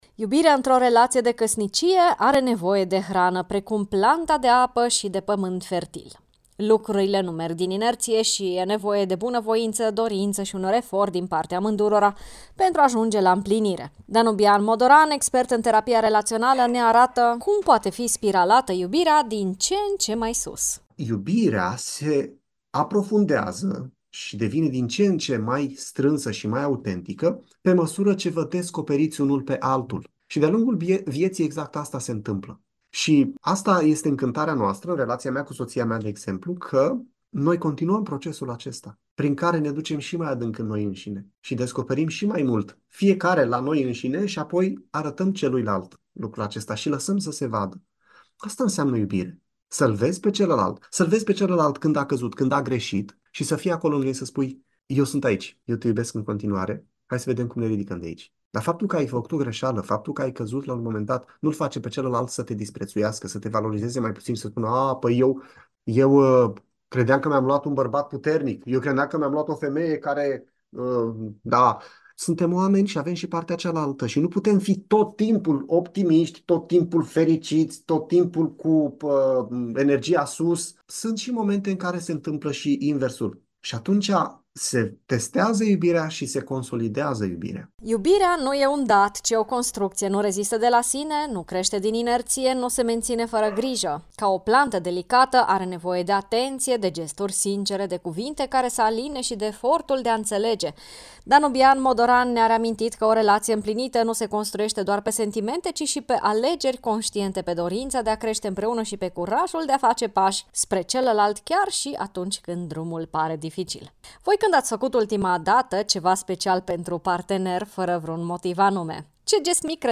terapeut de cuplu și familie: